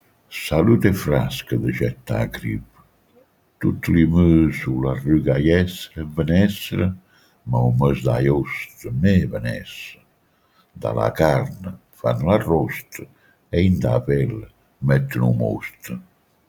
Questa tiritera si ode nelle campagne della Puglia piana, con inflessioni dei paesi garganici o cerignolani o di Bbascja marïne (Margherita, Barletta, Trinitapoli, ecc.).